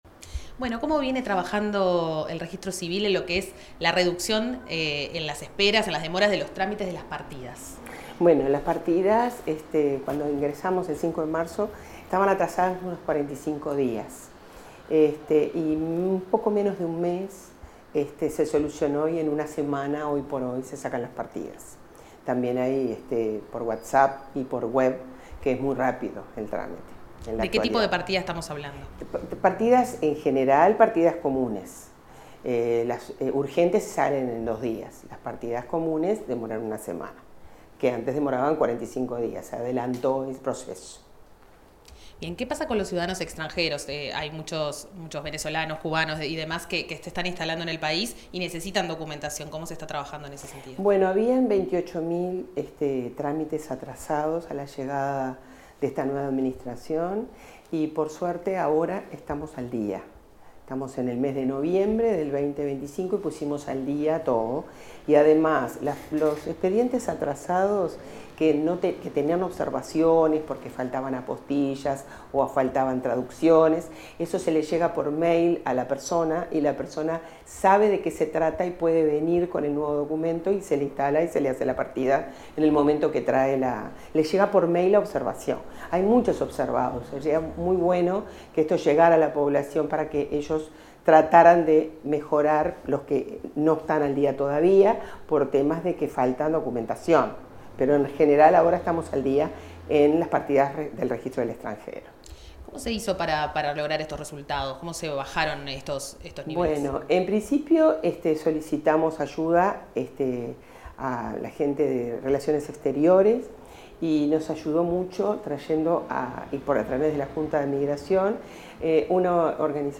Declaraciones de la directora nacional del Registro del Estado Civil, Silvia Facal | Presidencia Uruguay